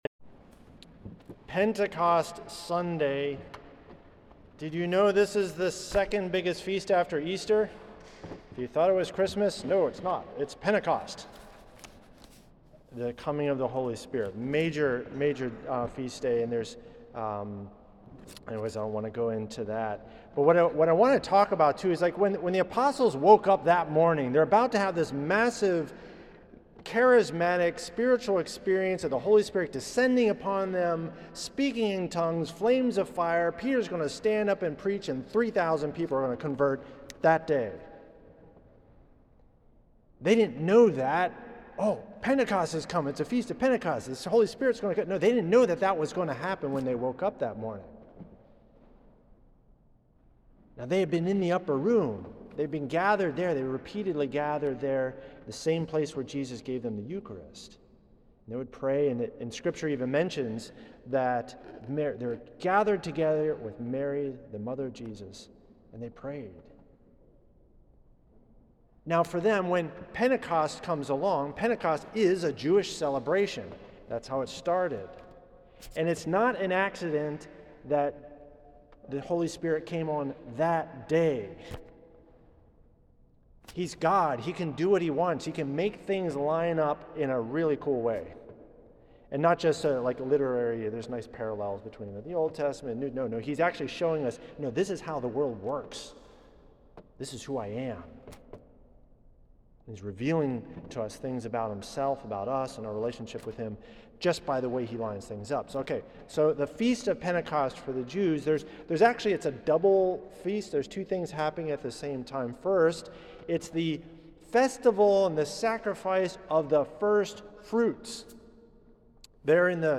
for Pentecost, May 19th, at St. Patrick’s Old Cathedral in NYC.